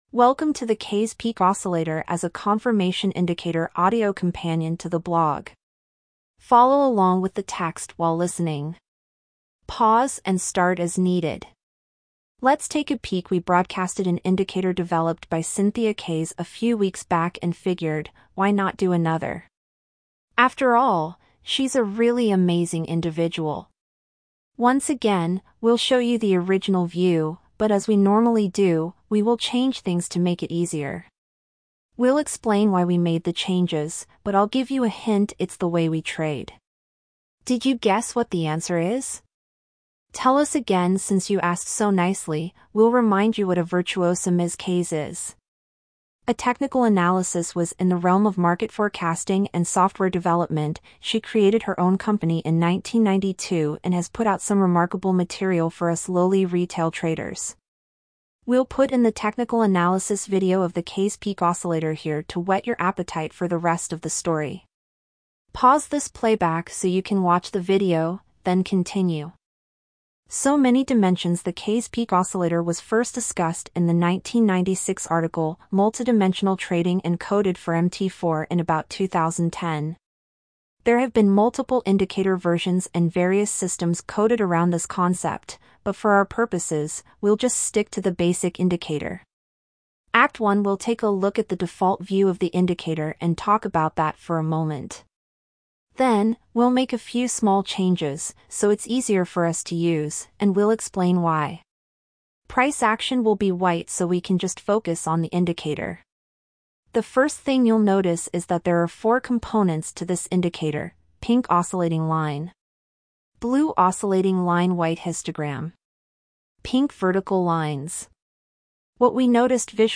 If you’d like to listen to the blog (like an e-book) while following the text, click “play” on the media player below.